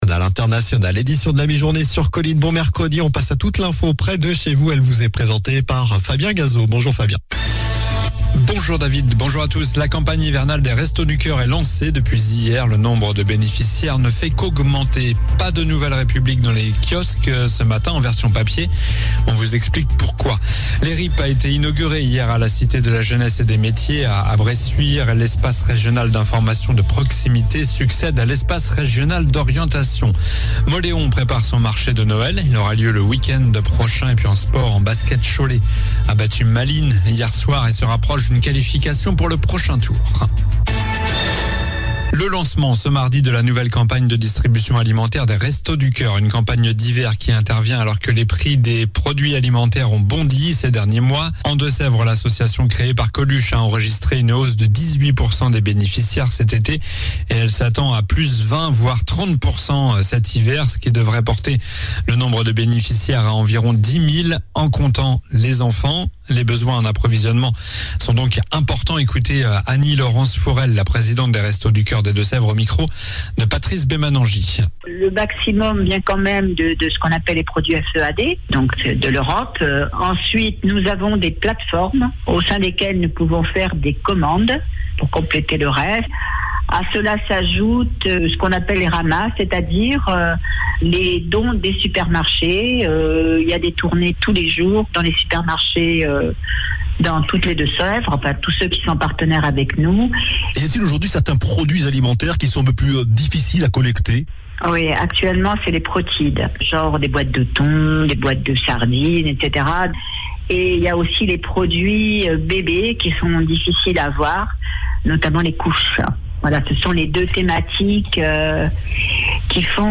Journal du mercredi 23 novembre (midi)